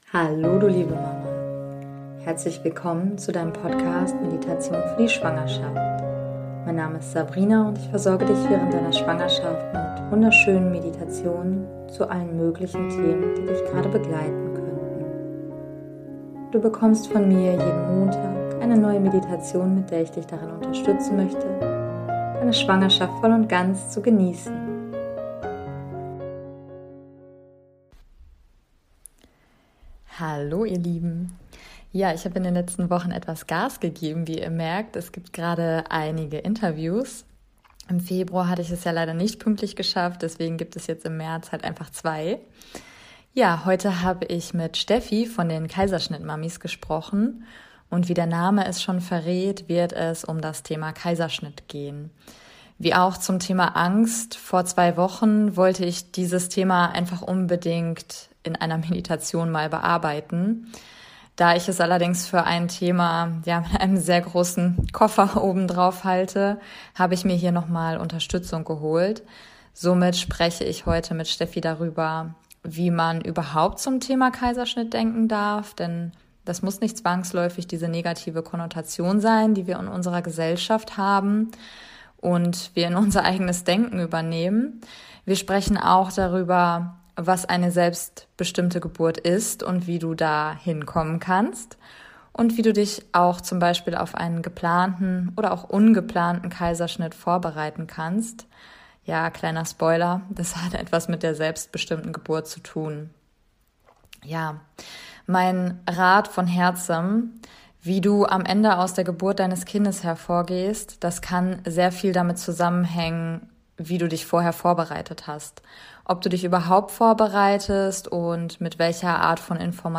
#023 - Traumgeburt Kaiserschnitt - Interview